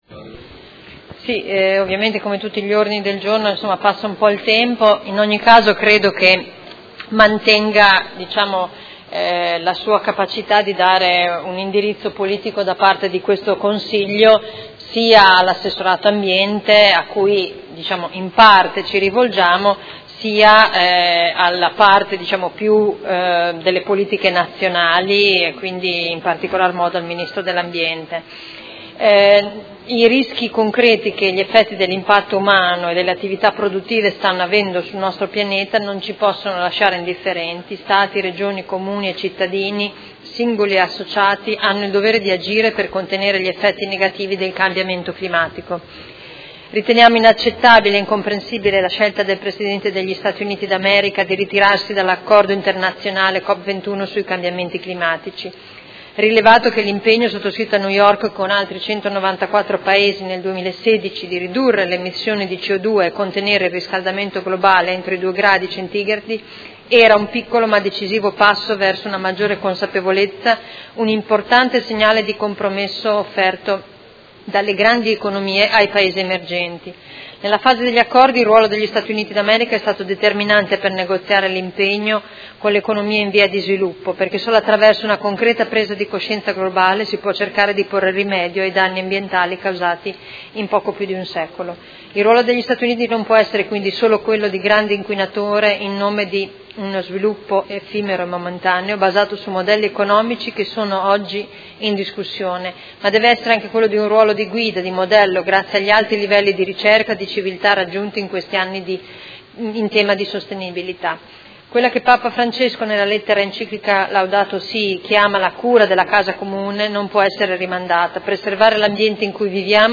Seduta del 9/11/2017. Ordine del Giorno presentato dai Consiglieri Arletti, Baracchi, Bortolamasi, Pacchioni, Forghieri, Di Padova, Venturelli, Poggi, Lenzini, Fasano e De Lillo (PD) avente per oggetto: Difesa dell’Accordo internazionale di COP21 sui cambiamenti climatici (Parigi 2015)